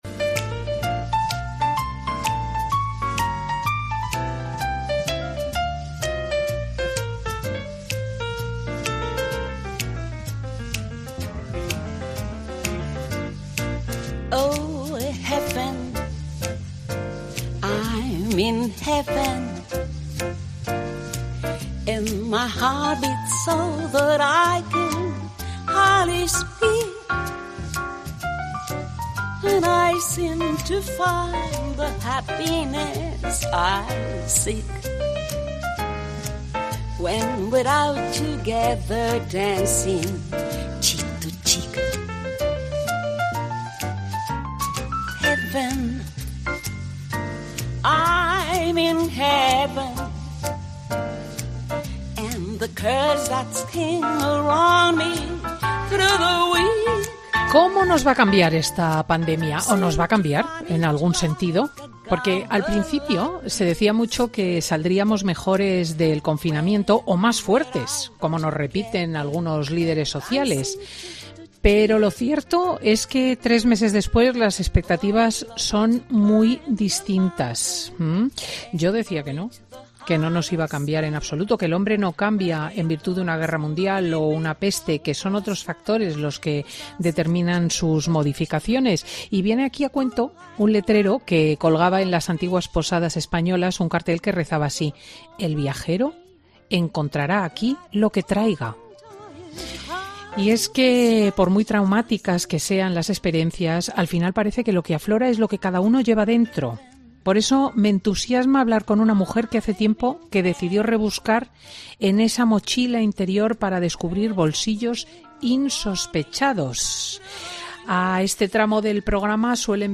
AUDIO: La artista comenta cómo ha llevado el confinamiento y qué opina de las medidas del Gobierno
Vamos a hablar con Paloma San Basilio, es cantante, actriz, pintora y escritora y ha venido a Fin de Semana a hablar sobre el confinamiento, pero también de las proezas que ha hecho durante su vida.